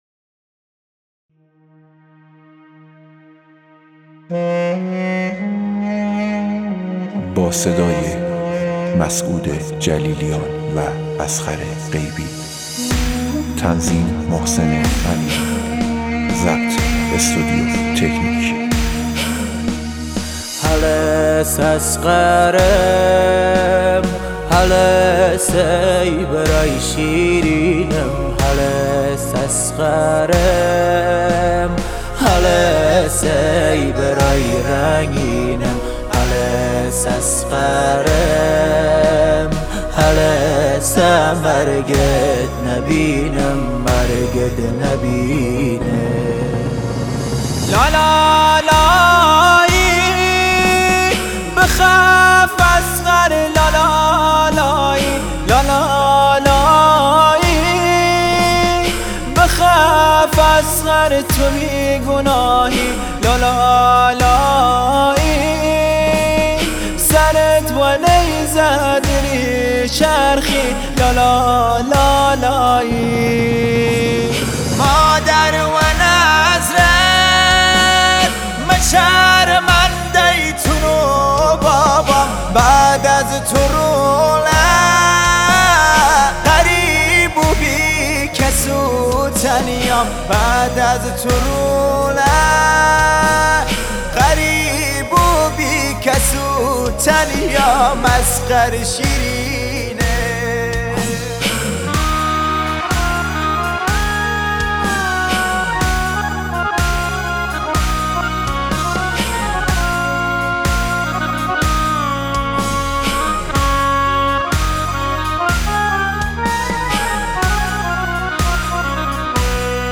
مداحی کردی